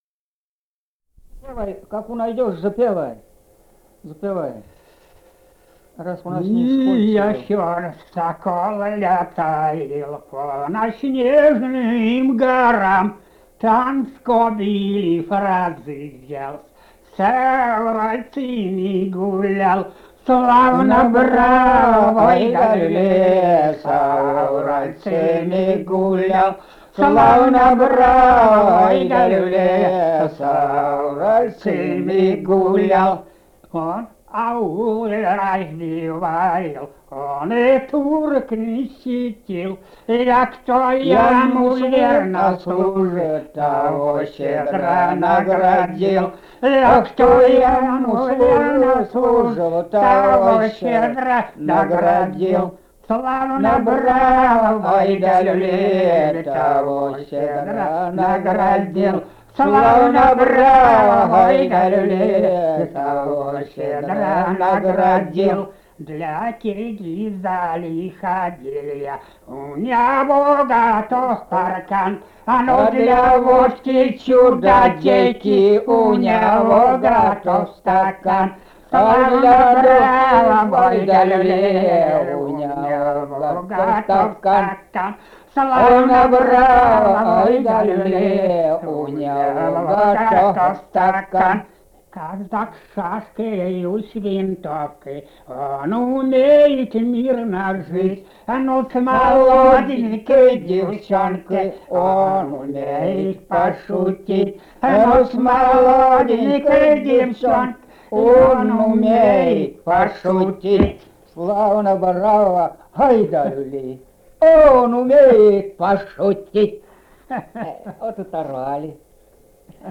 Этномузыкологические исследования и полевые материалы
«Не ясён сокол летает» («кавалерийская, на рысях»).
Казахстан, г. Уральск, 1972 г. И1312-24